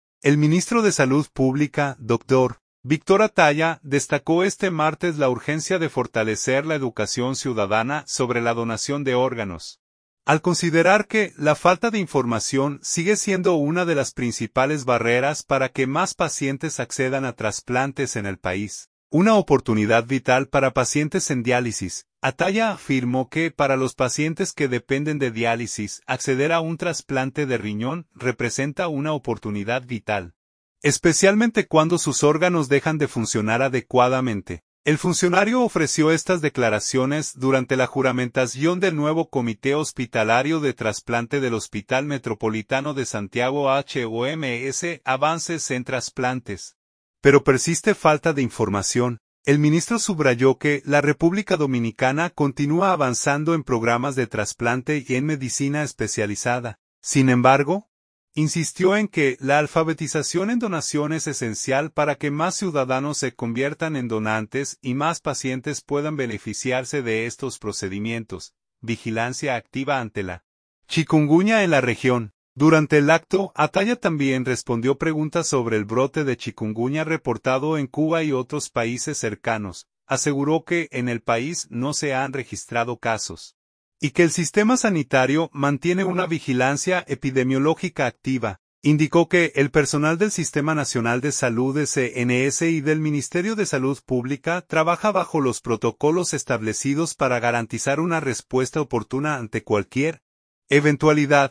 El funcionario ofreció estas declaraciones durante la juramentación del nuevo Comité Hospitalario de Trasplante del Hospital Metropolitano de Santiago (HOMS).